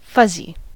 fuzzy: Wikimedia Commons US English Pronunciations
En-us-fuzzy.WAV